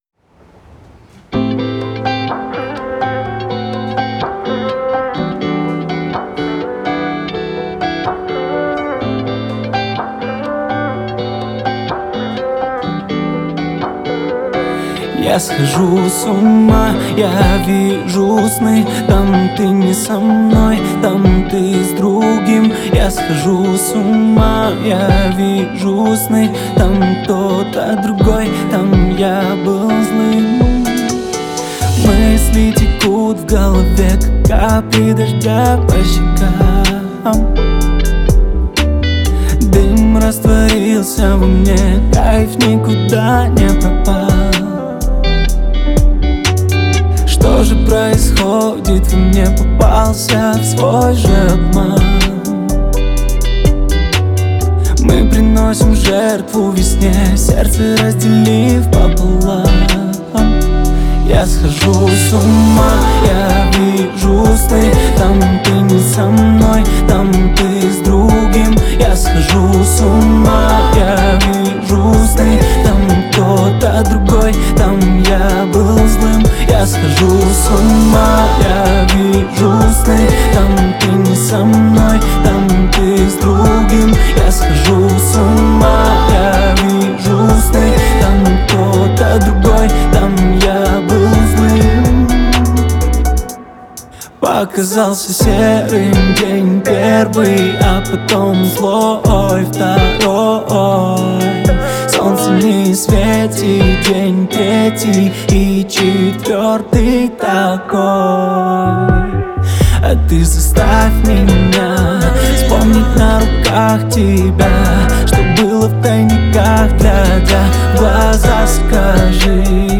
в жанре поп, наполненная энергией и чувственными мелодиями.